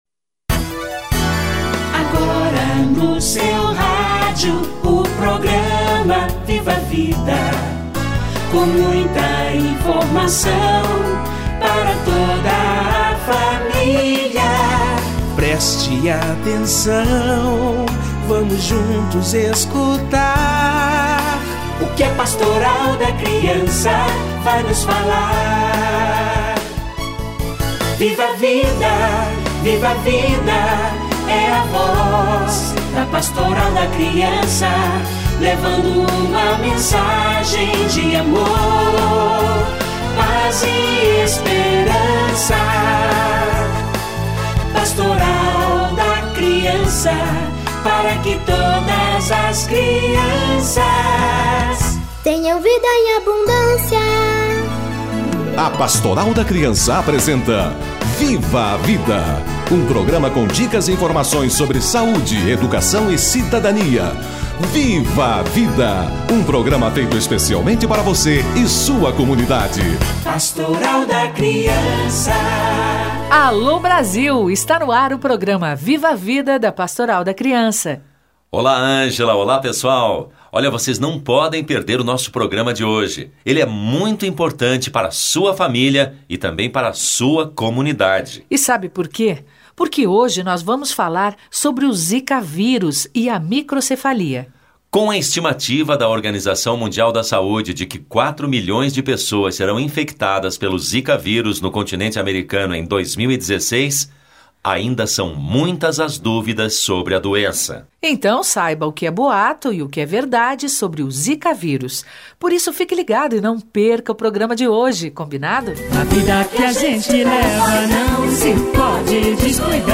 Zika e Microcefalia - Entrevista